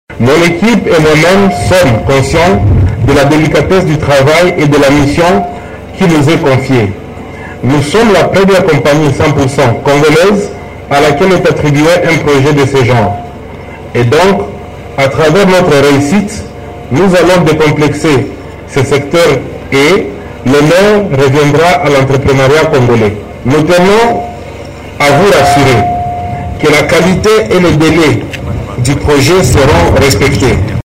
La cérémonie du lancement s’est tenue sur le site du projet, qui sera exécuté par la firme Vaste Réseau de Service au Congo (VRSC).